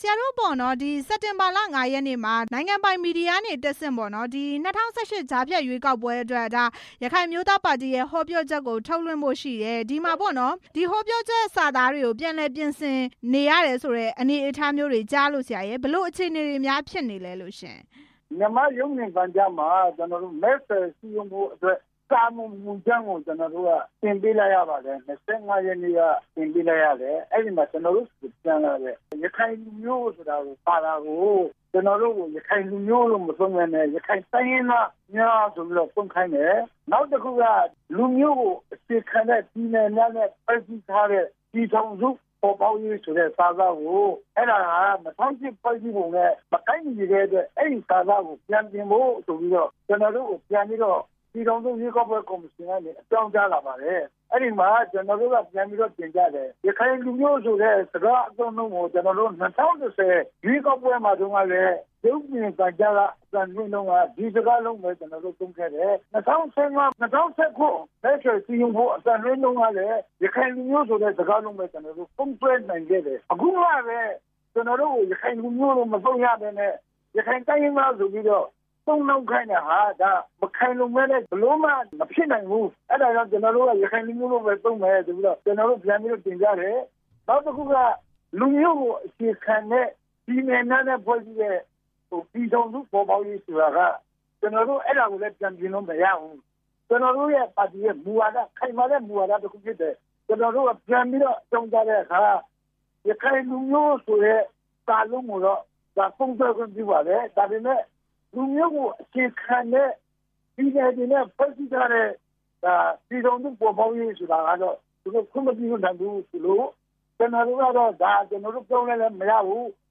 ANP ရွေးကောက်ပွဲ မဲဆွယ်ဟောပြောချက်ကိစ္စ ဆက်သွယ်မေးမြန်းချက်